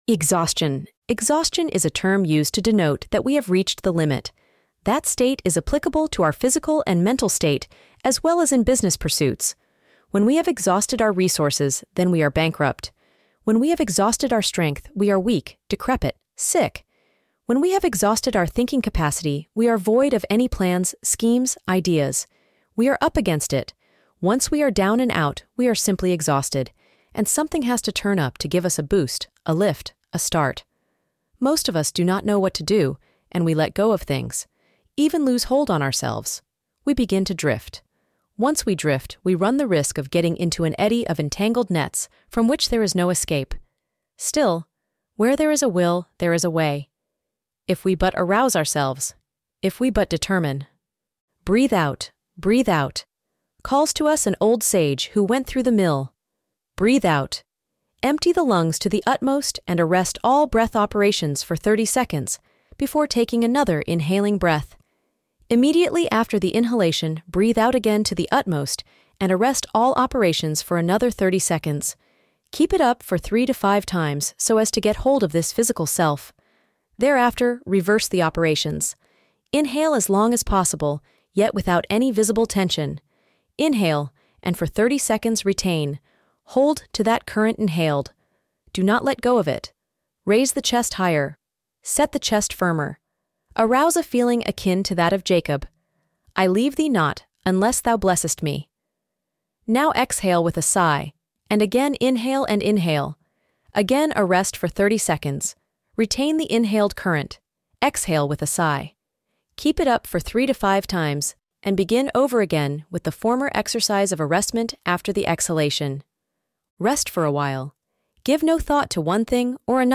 (spoken by Elevenlabs Matilda)